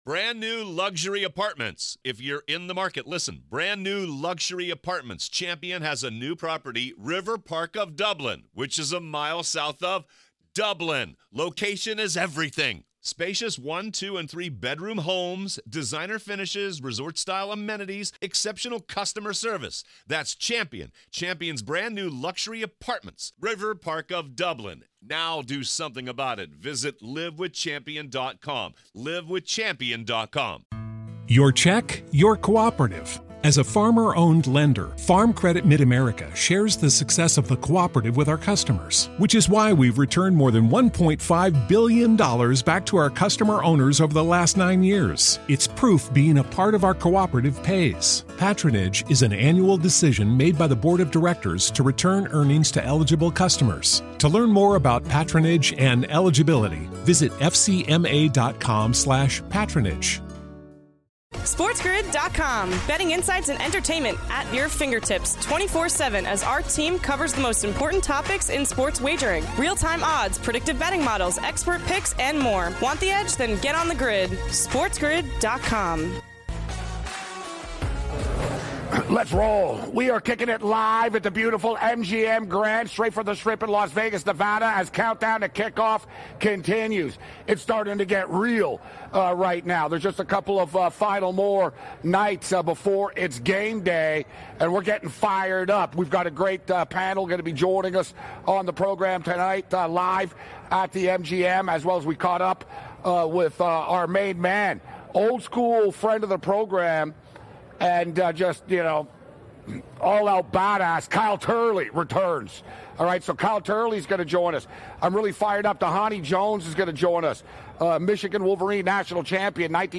live from Radio Row in Vegas. The hour includes interviews with betting analyst